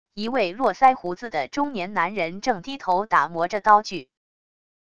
一位络腮胡子的中年男人正低头打磨着刀具wav音频